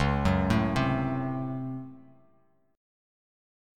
DbM7sus4#5 chord